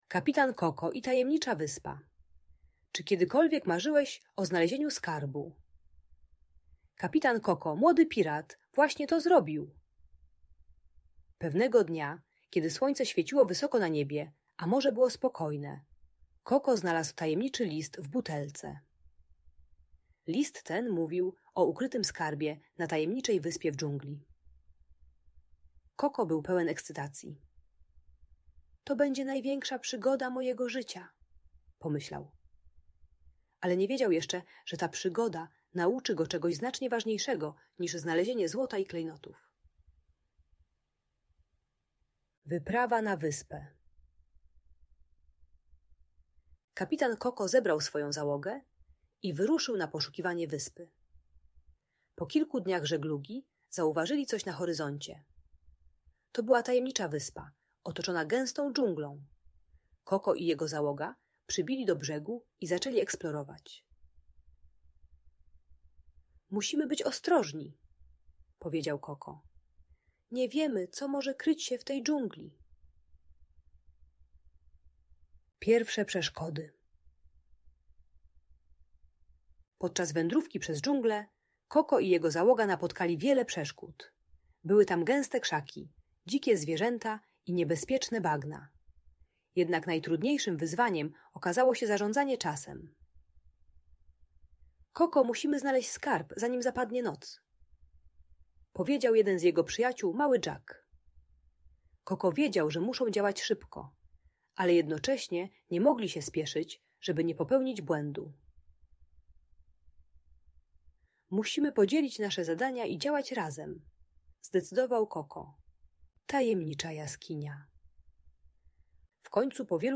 Kapitan Koko i Tajemnicza Wyspa - Piracka Story - Audiobajka